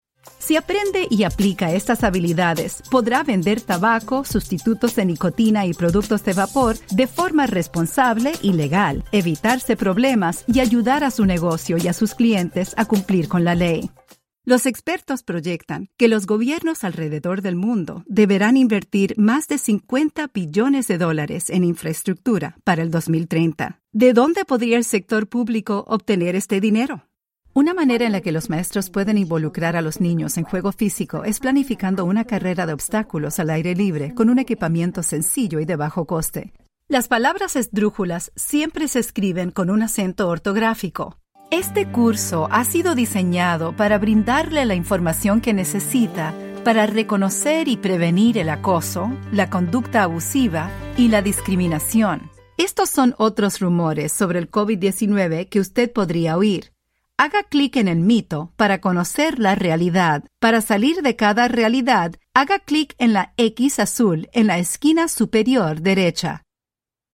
E-learning
Mis clientes describen mejor mi voz como amigable, chispeante, expresiva, agradable, cálida y entusiasta.